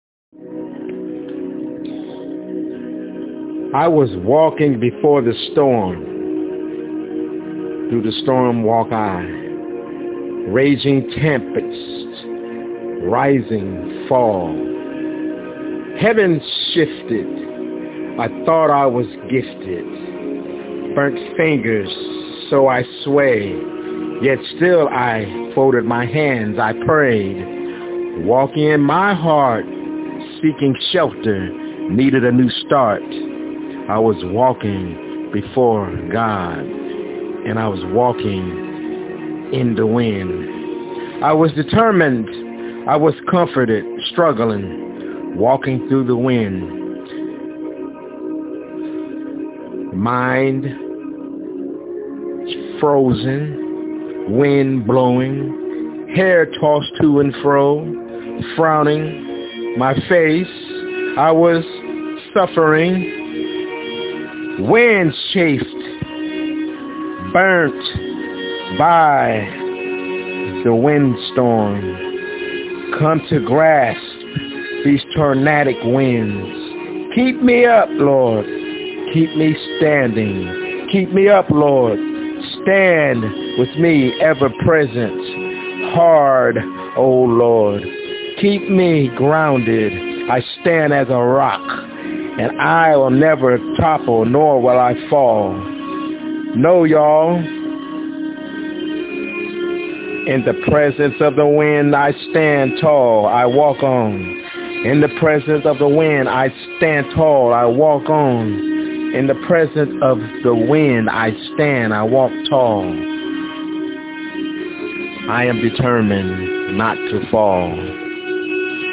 Walkings In the Winds- My Spokenword